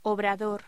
Locución: Obrador
voz